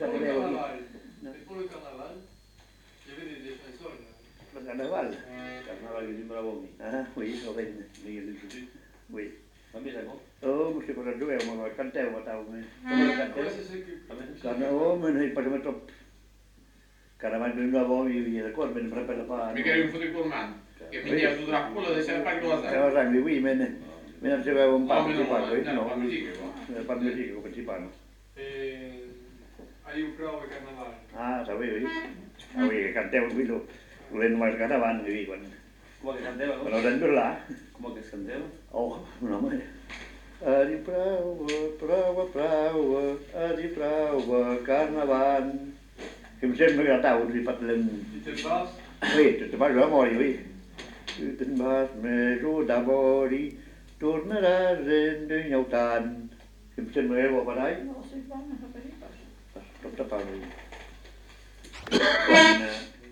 Lieu : Lencouacq
Genre : chant
Type de voix : voix d'homme
Production du son : chanté
Description de l'item : fragment ; 1 c. ; refr.
Classification : chanson de carnaval